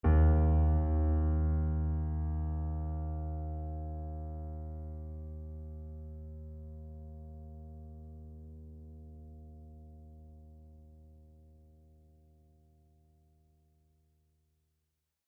piano-sounds-dev
GreatAndSoftPiano